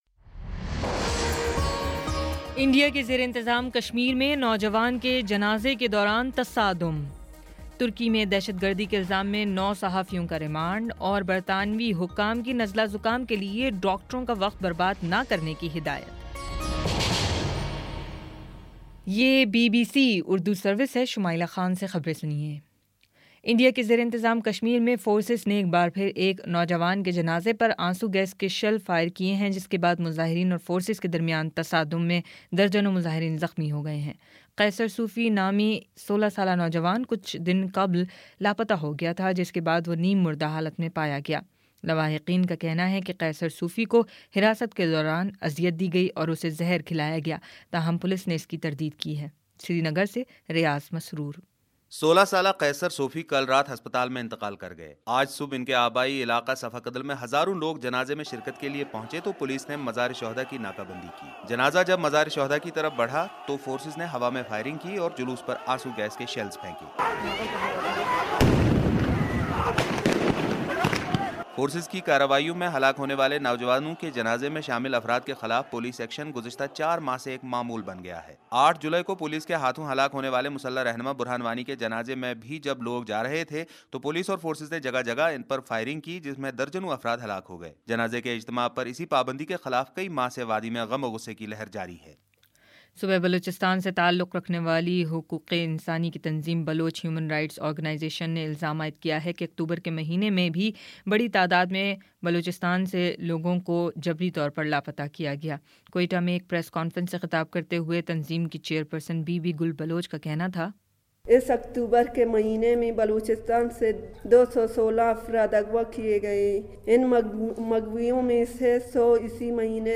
نومبر 05 : شام سات بجے کا نیوز بُلیٹن